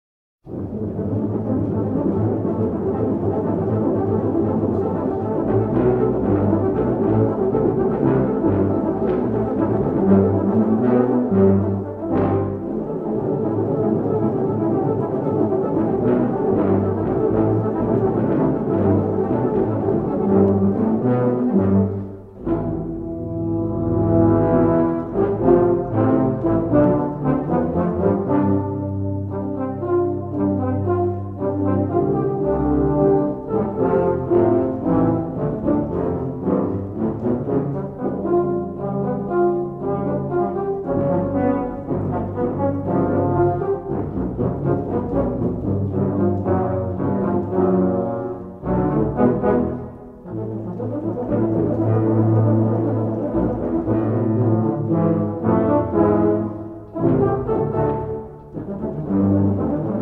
Tuba